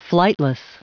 Prononciation du mot flightless en anglais (fichier audio)
Prononciation du mot : flightless